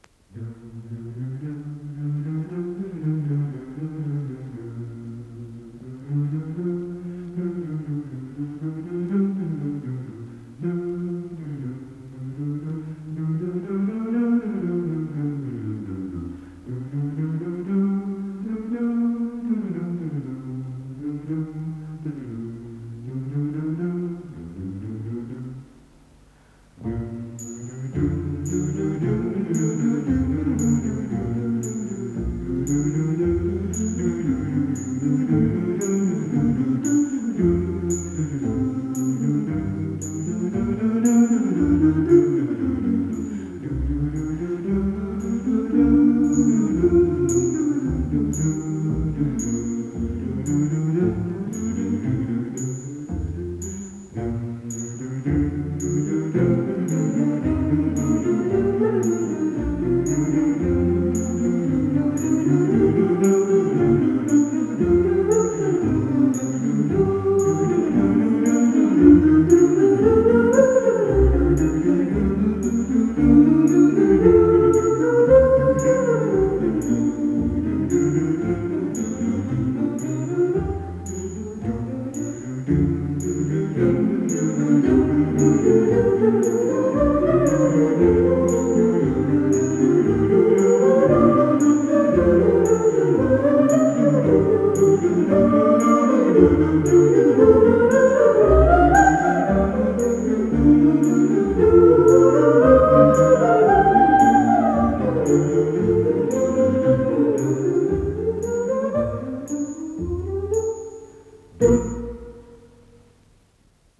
с профессиональной магнитной ленты
ИсполнителиВокально-инструментальный ансамбль "Свингл сингерс"
ВариантДубль моно